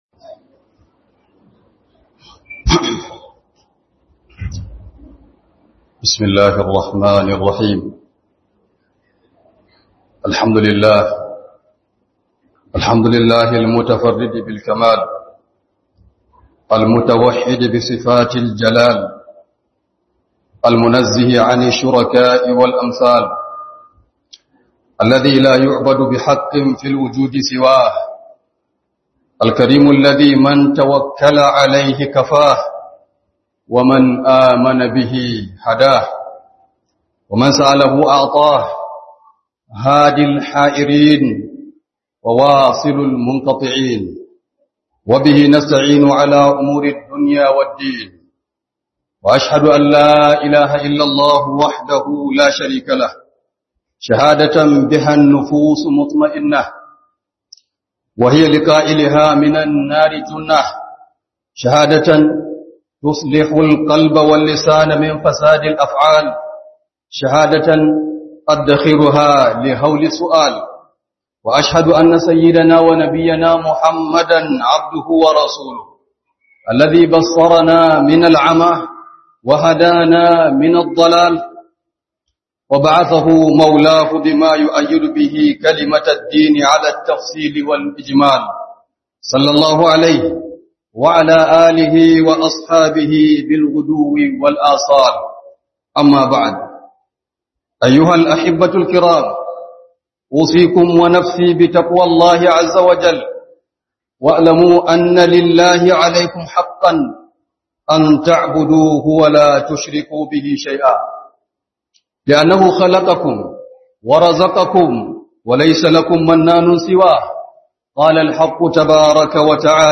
Book HUDUBA